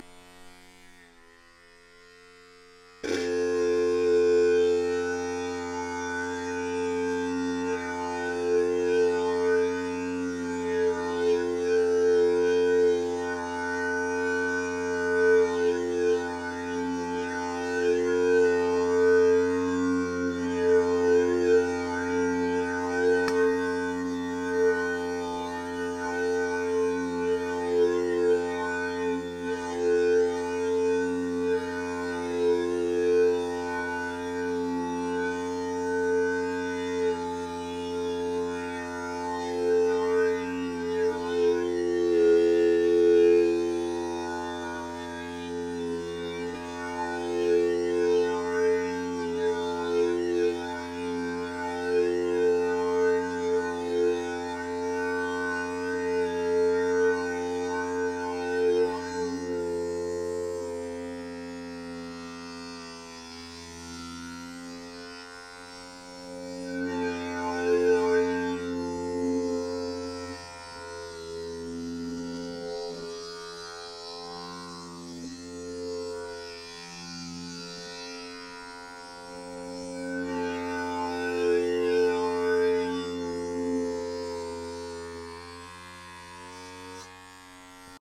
An example of a sound created using only the technique of studio recording:
‘Electric Toothbrush on bowl’ – An example of a sound created using only the technique of studio recording
No additional processing.